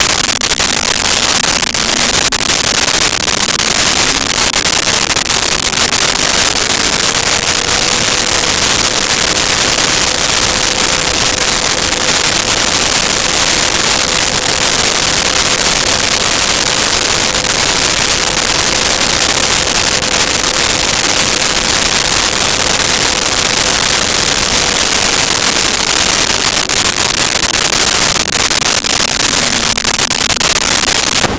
Printer Start Warm Up
Printer Start Warm Up.wav